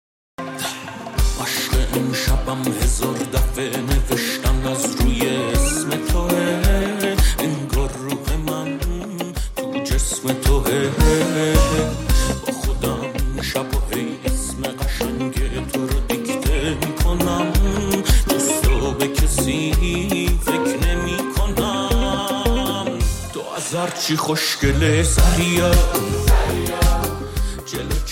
از افکت‌های آماده این ابزار برای ساخت ریمیکس استفاده شده!